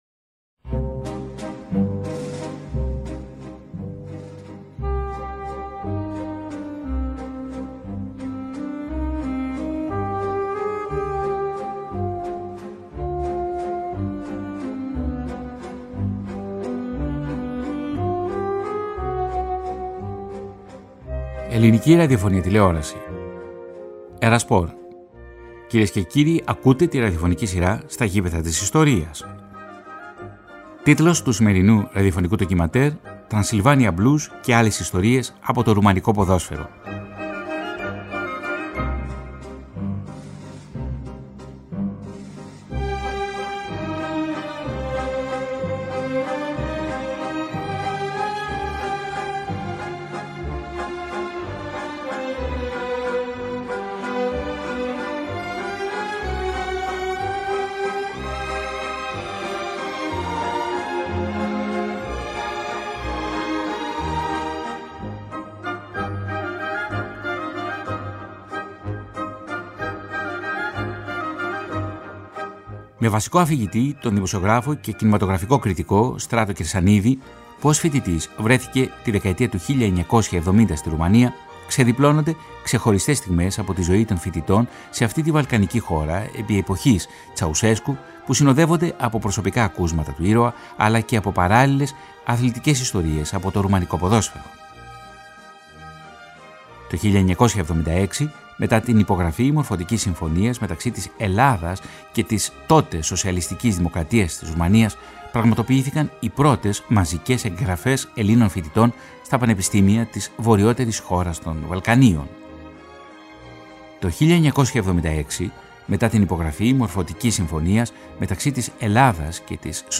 Ένα ξεχωριστό και πολύ ενδιαφέρον ραδιοφωνικό ντοκιμαντέρ παρουσίασε σήμερα, η ραδιοφωνική σειρά της ΕΡΑ ΣΠΟΡ “Στα γήπεδα της Ιστορίας”.